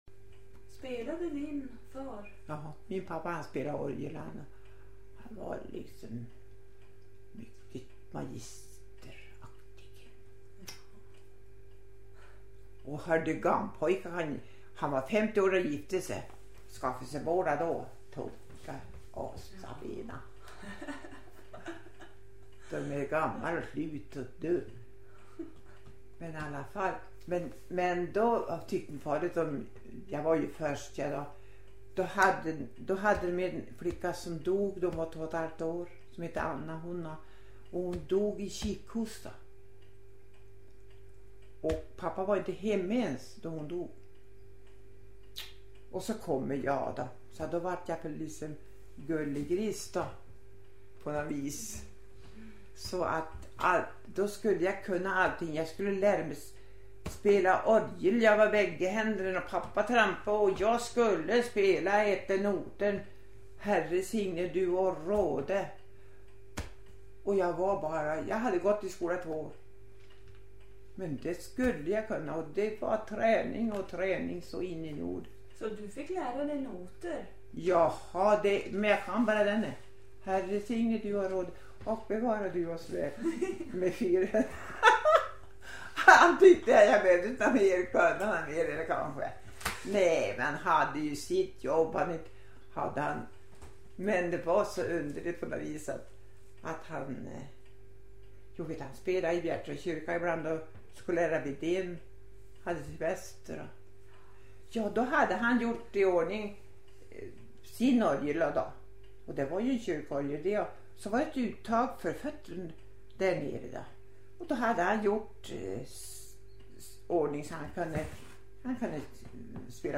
ljudpost